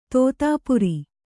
♪ tōtāpuri